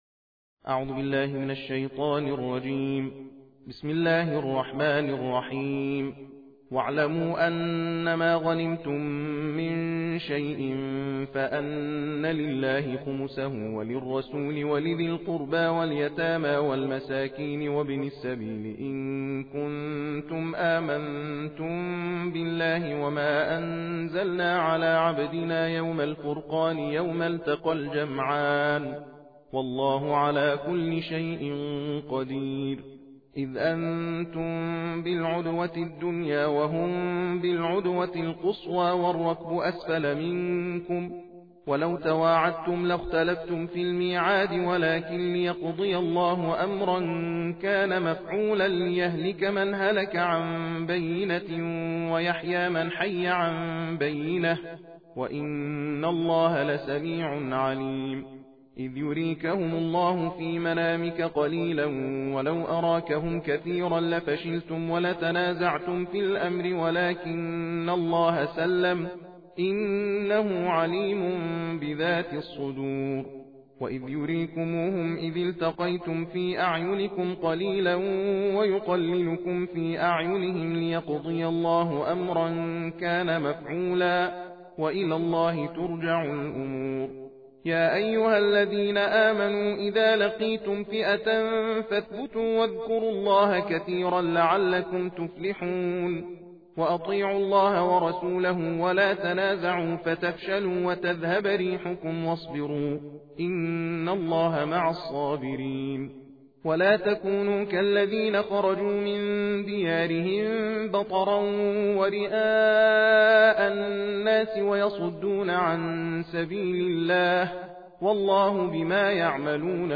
صوت/تلاوت جزءدهم قرآن کریم